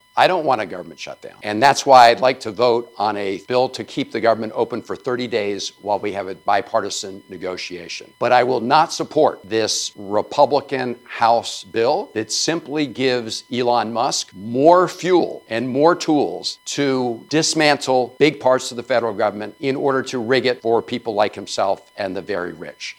Senator Chris Van Hollen released a video statement Thursday afternoon on his intentions surrounding legislation to prevent a government shutdown. The Senator said a bill needs passed that will represent the best of the American people, not benefit specific classes…